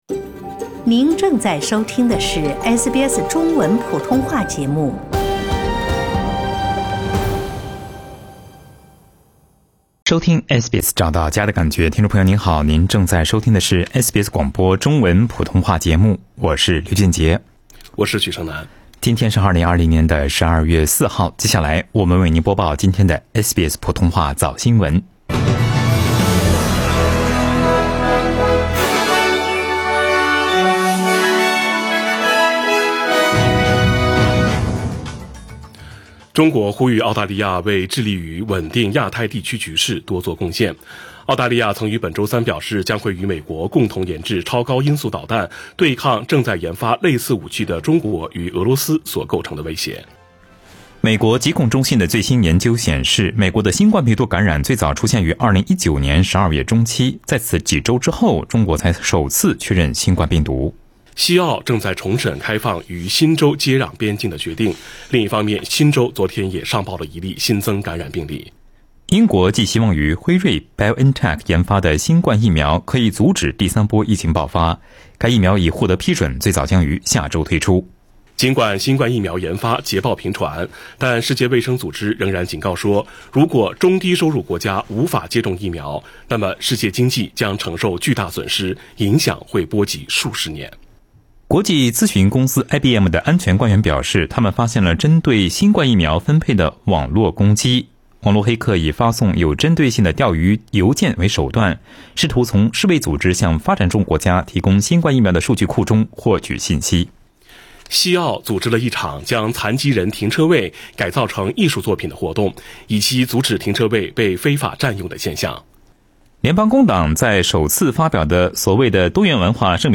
SBS早新聞（12月04日）